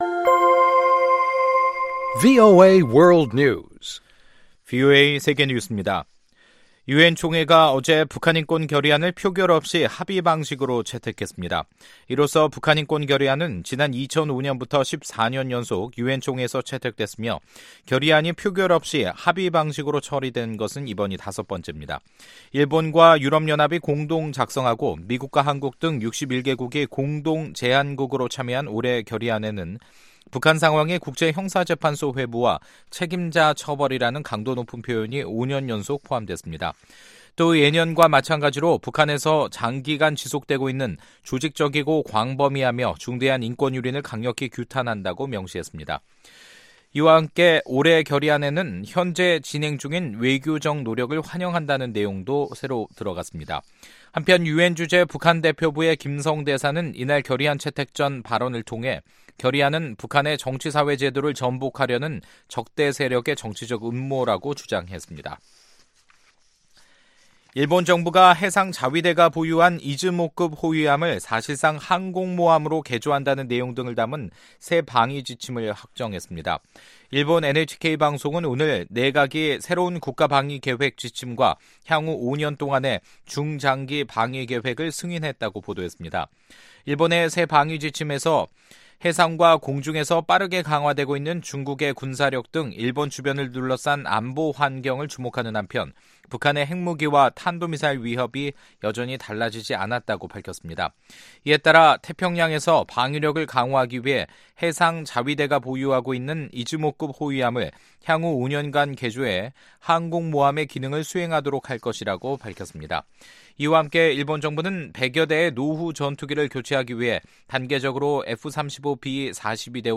VOA 한국어 간판 뉴스 프로그램 '뉴스 투데이', 2018년 12월 18일 2부 방송입니다. 유엔총회가 북한 정권의 인권 유린에 대한 채임 규명과 처벌을 북한인권결의안을 채택했습니다. 미국 주요 언론들은 최근 북한이 미국의 제재 압박을 비난하면서도 트럼프 대통령을 직접 비난하지 않은 것은 2차 미-북 정상회담에 대한 의지를 드러낸 것이라고 분석했습니다.